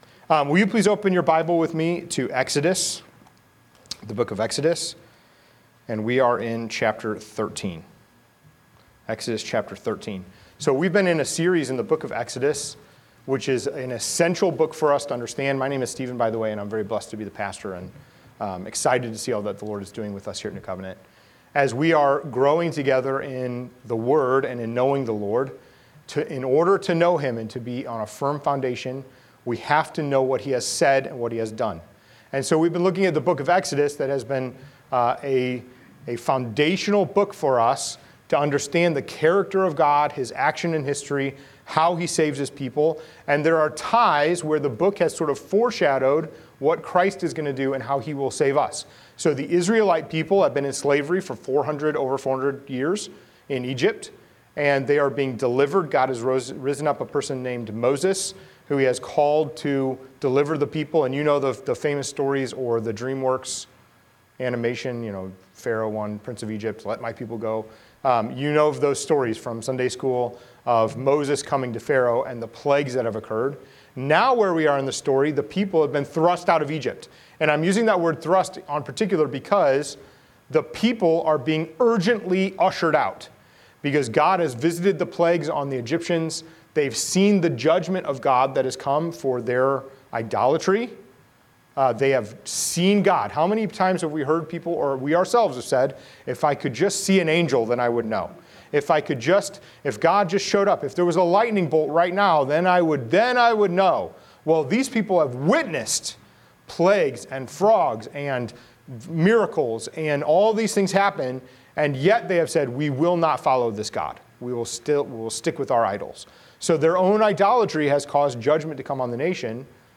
Sermons » 6-22-25